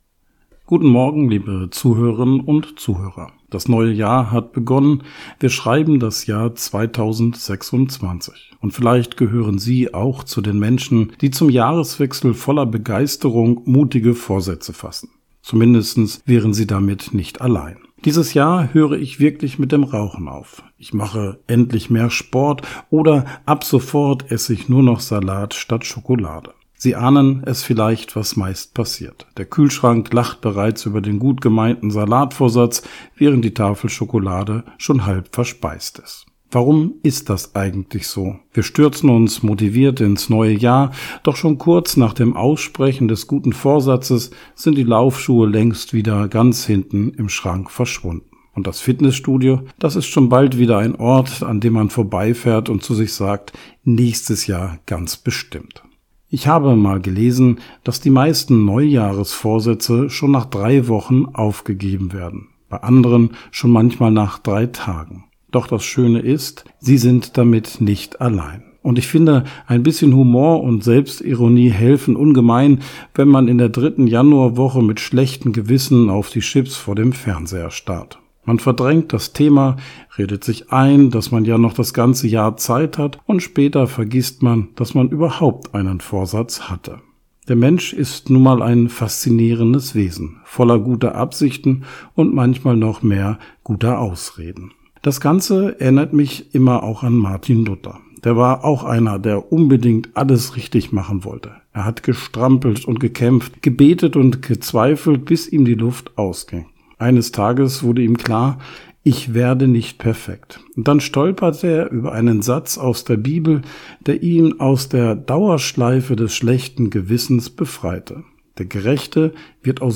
Radioandacht vom 2. Januar